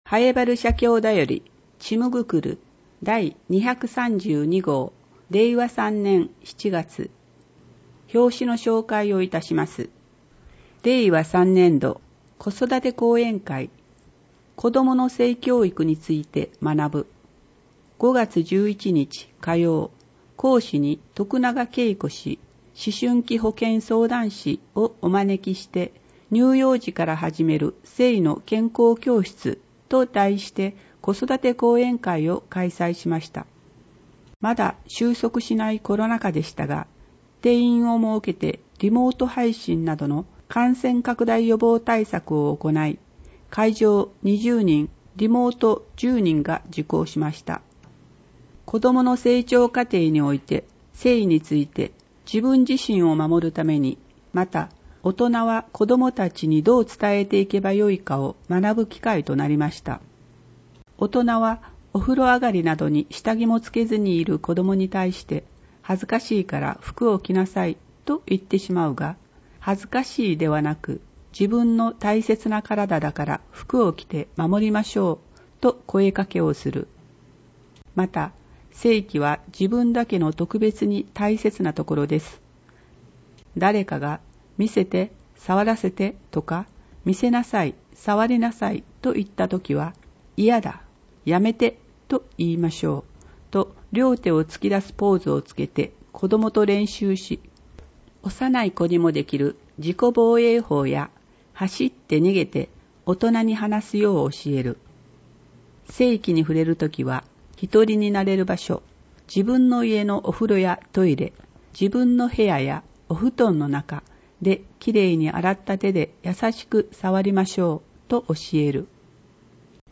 以下は音訳ファイルです